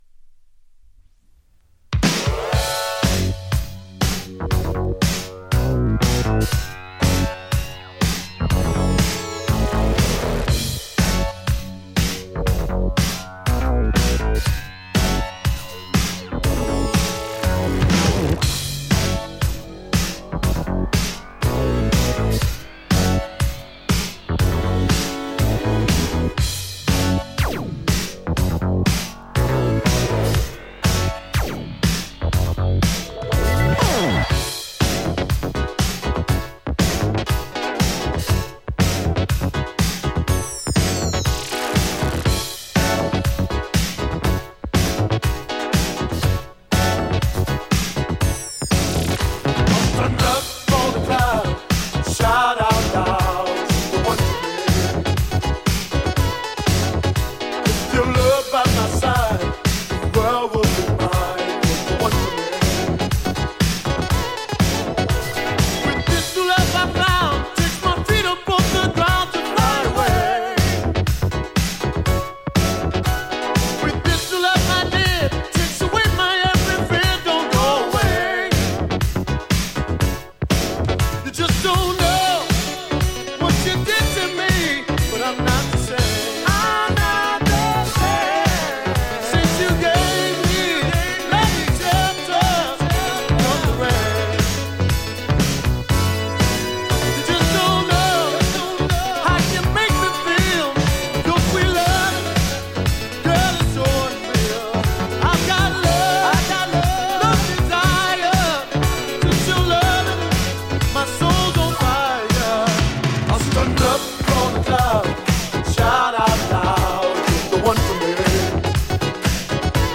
パンチが効いたダンサブルでブギーな名曲。
ジャンル(スタイル) FUNK / SOUL / DISCO / ELECTRONIC FUNK